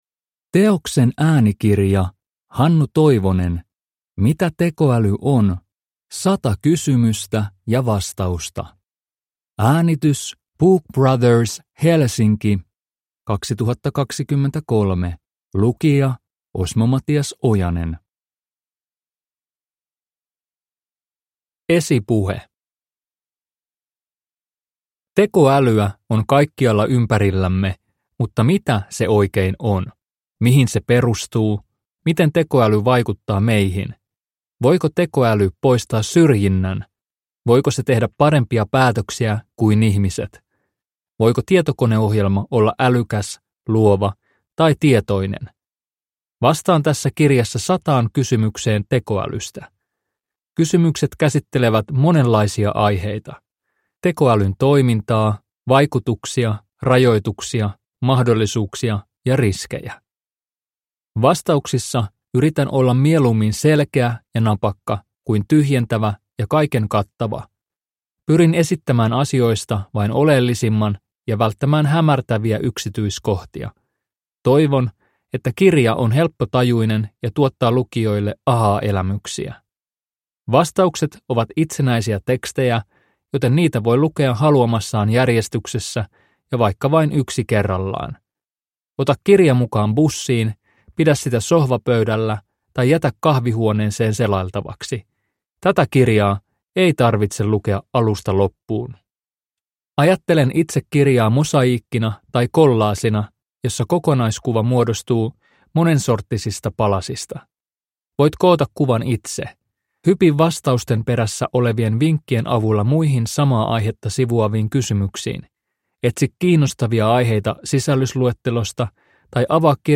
Mitä tekoäly on? – Ljudbok